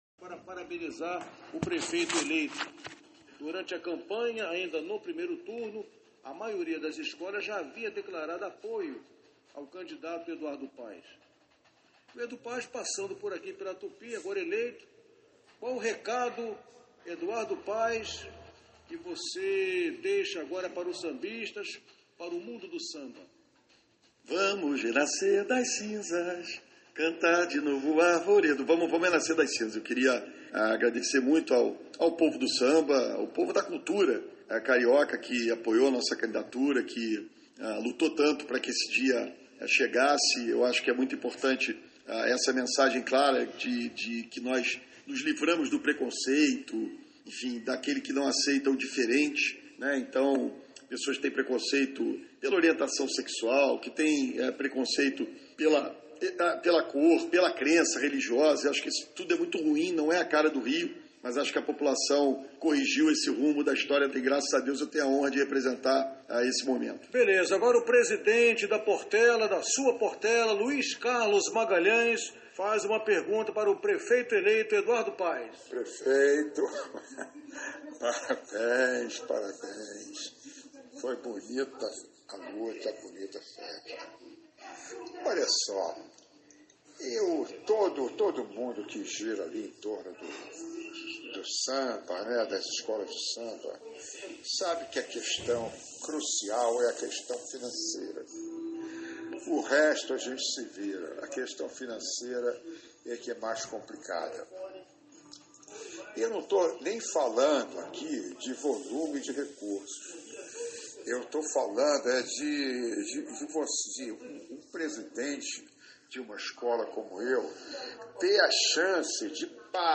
O prefeito eleito da cidade do Rio de Janeiro, Eduardo Paes, confirmou no programa Show de Bola deste sábado (05), que as escolas de samba voltarão a receber o subsídio para o carnaval de 2021.